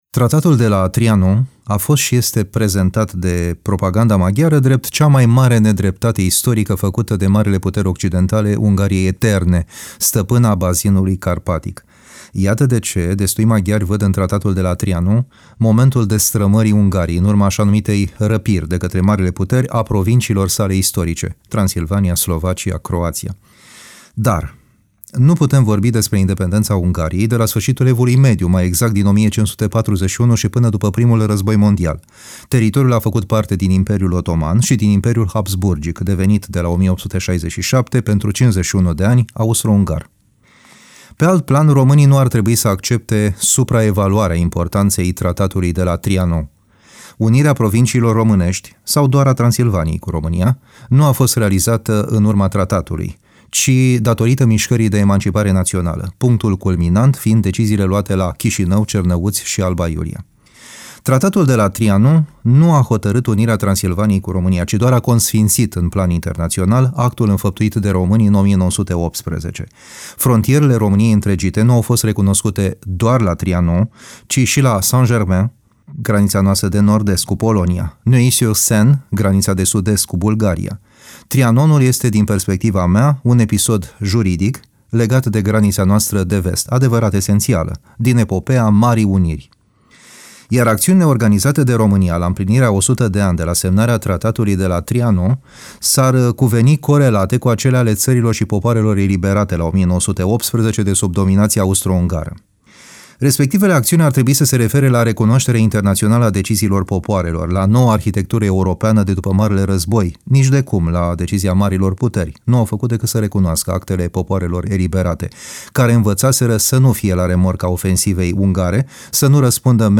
comentariu